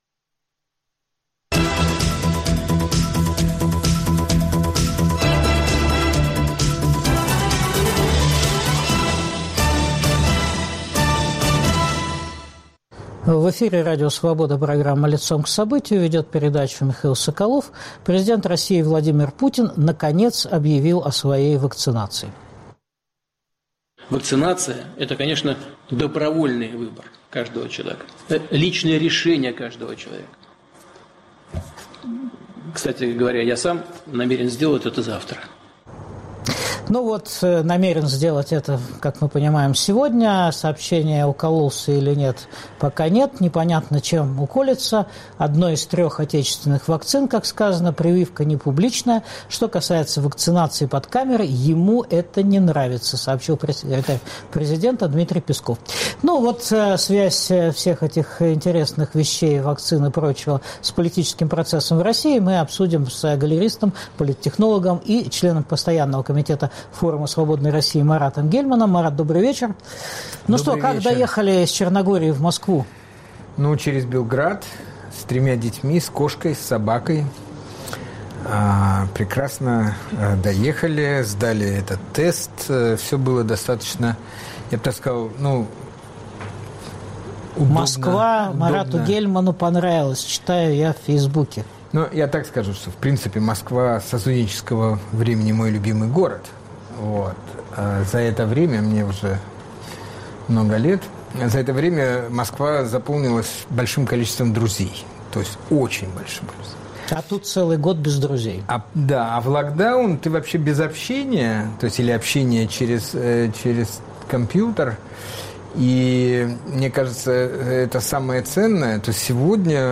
Как повлияет предстоящий выход Путина из бункера на политический процесс в России? Обсуждаем с галеристом, политтехнологом членом Постоянного комитета Форума Свободной России Маратом Гельманом.